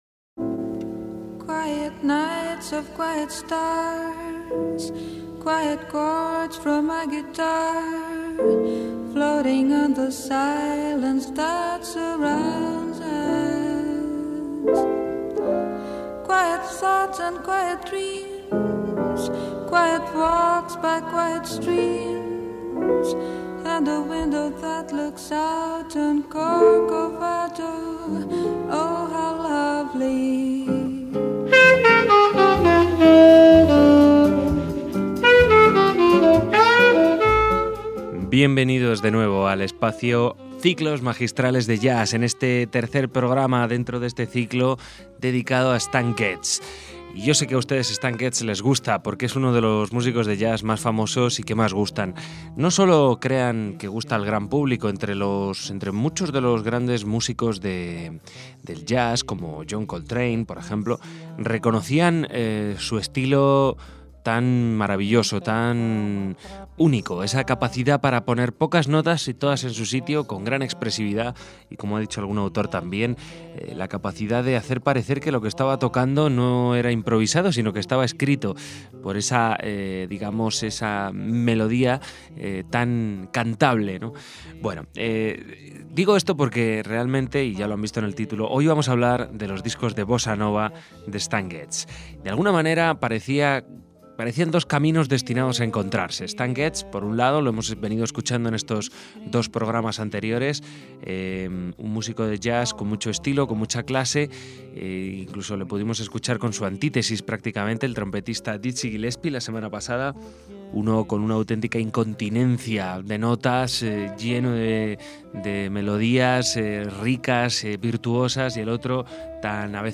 saxos tenores
por su tono cálido y economía de notas
impregnado de los ritmos y armonías del bossa nova